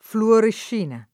fluorescina [ fluorešš & na ] s. f. (chim.)